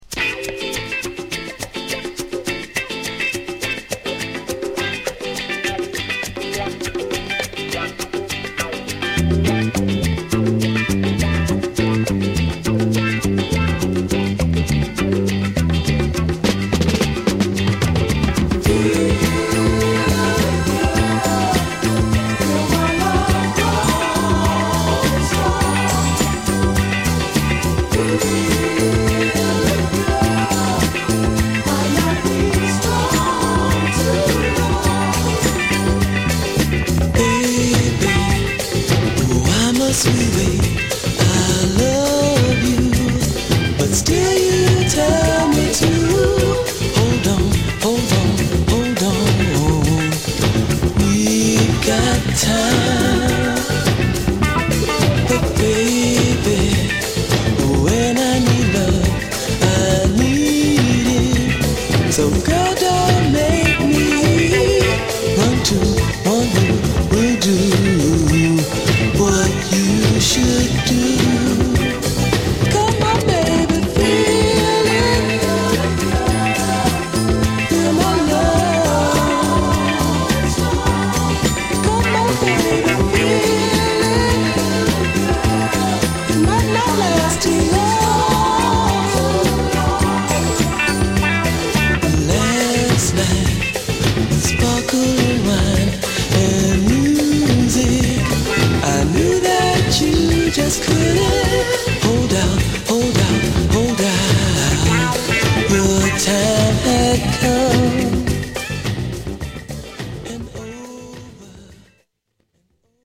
(Stereo)